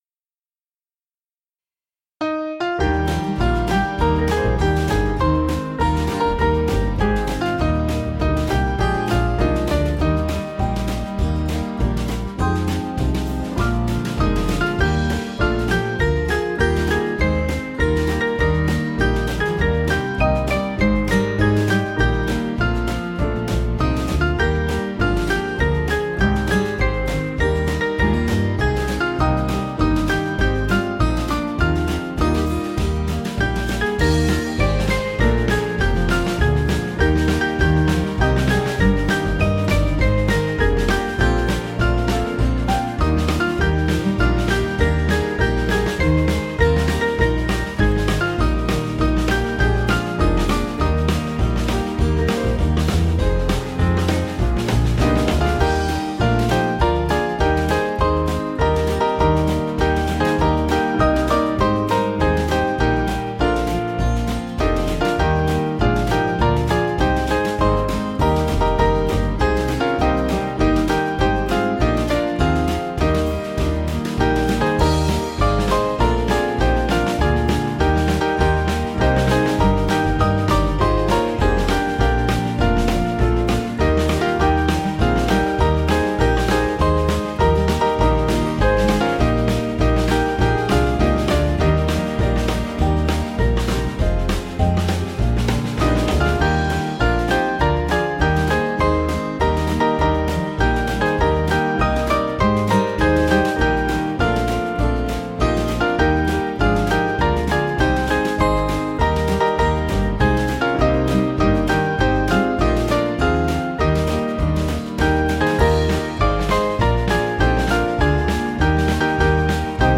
Small Band
(CM)   3/Eb 478.9kb